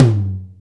TOM KLB TO05.wav